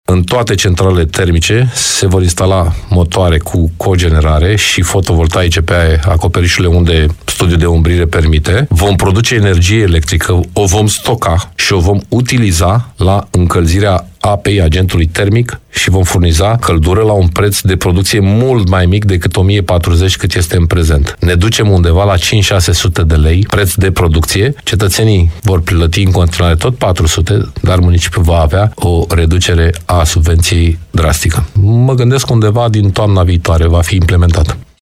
Prezent ieri, la Radio Constanța, în emisiunea Dialoguri la Zi, Paul Foleanu a anunțat că sistemul de termoficare se va moderniza și va deveni mai eficient – un grup de investiții german va crea un sistem bazat pe cogenerare și fotovoltaice